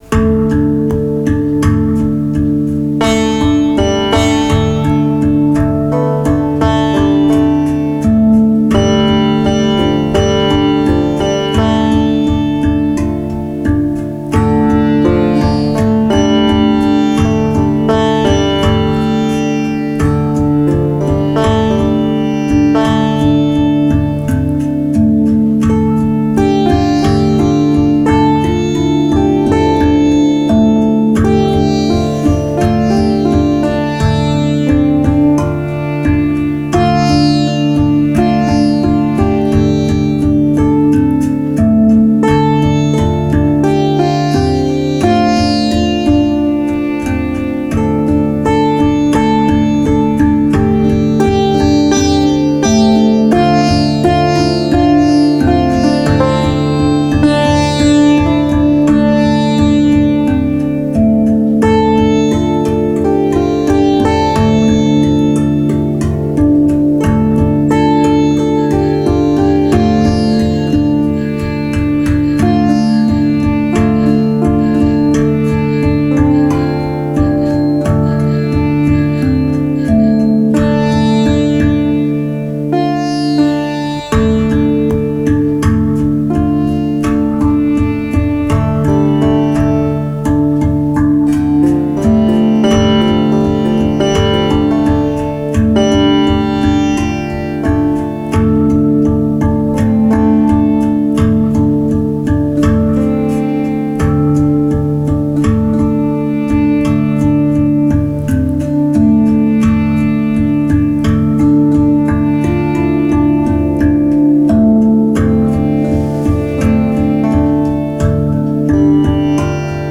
st25_312_sitar.mp3